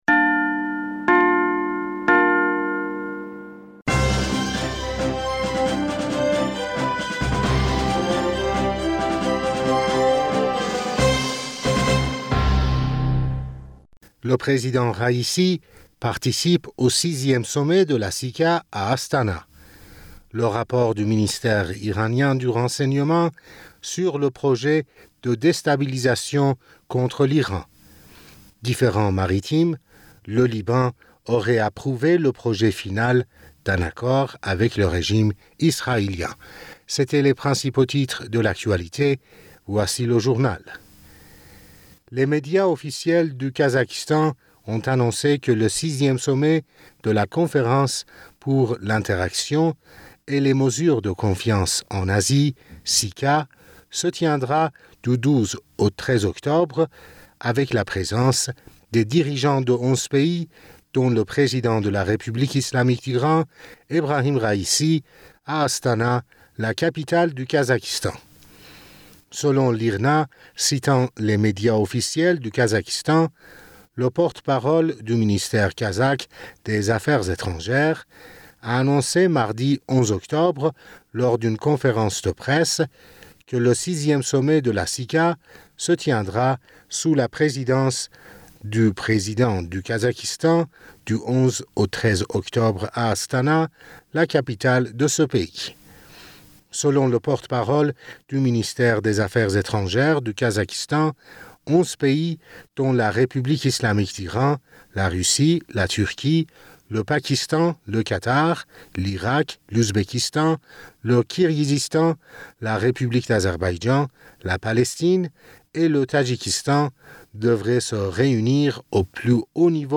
Bulletin d'information Du 11 Octobre